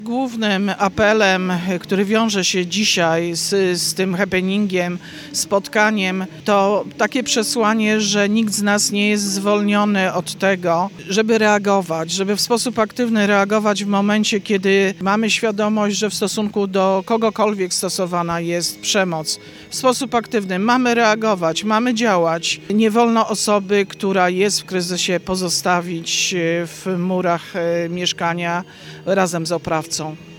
W ramach tej międzynarodowej inicjatywy, w Stargardzkim Teatrze Letnim zorganizowano dzisiaj happening, którego celem było zwrócenie uwagi na problem przemocy wobec najmłodszych.
Iwona Wiśniewska, starosta stargardzka, podczas wydarzenia podkreśliła, jak ważne jest aktywne reagowanie, gdy jesteśmy świadkami przemocy. – Głównym przesłaniem dzisiejszego happeningu, tego spotkania, jest apel, że nikt z nas nie może pozostawać obojętny.